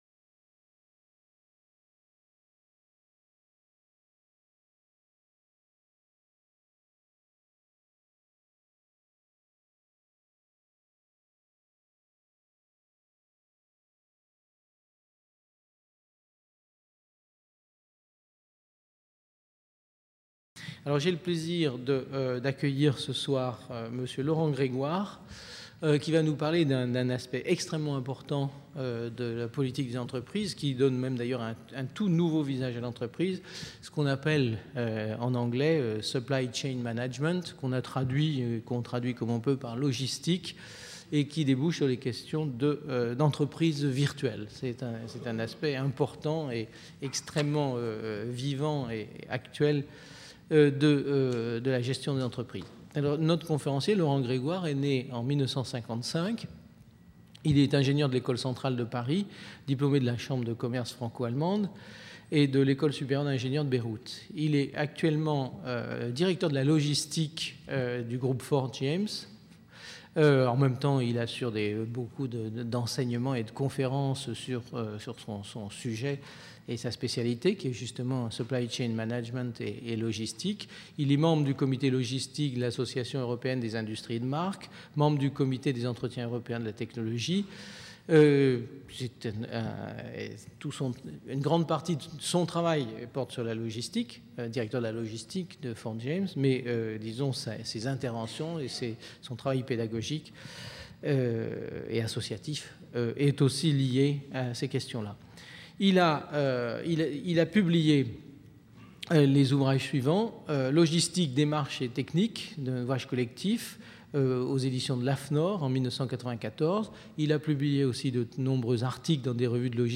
Cette conférence relève d'une triple incongruité.